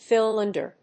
音節phi・lán・der・er 発音記号・読み方
/‐dərɚ(米国英語), ‐dərə(英国英語)/